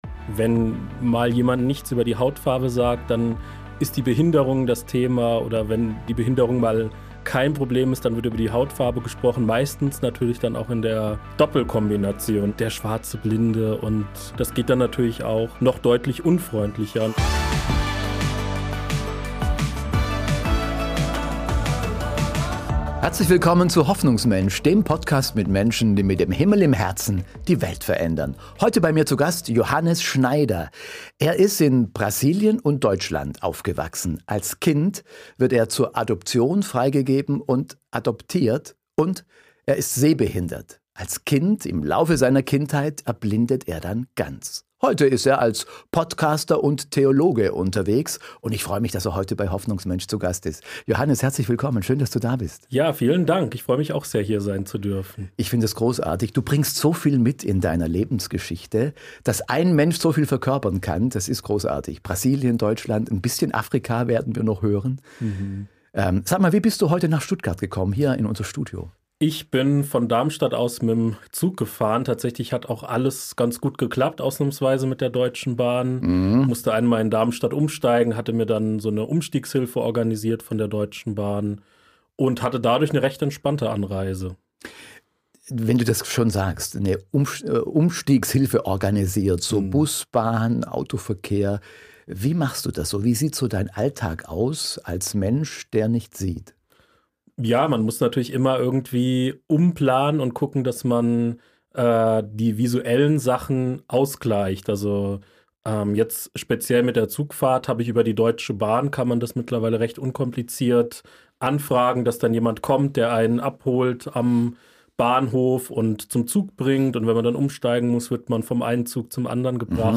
Ein Gespräch über Gott, Identität, Ausgrenzung – und die Kraft einer Hoffnung, die weiterträgt.